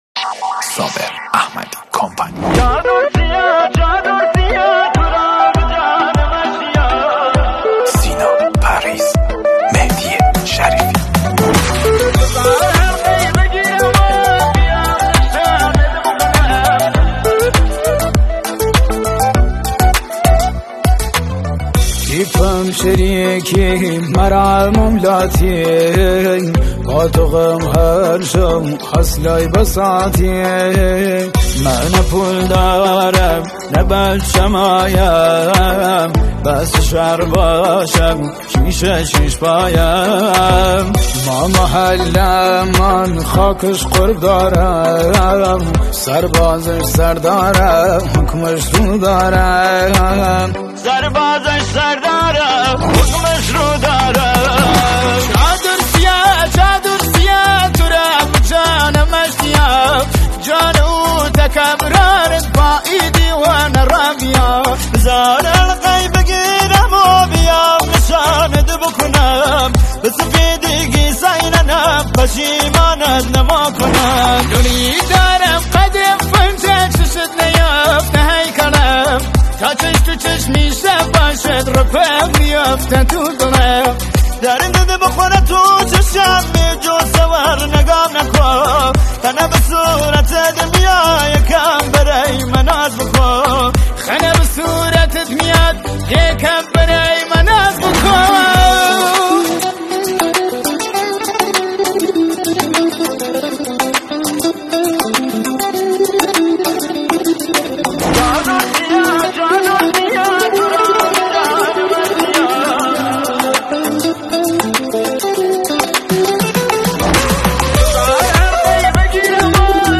اهنگ محلی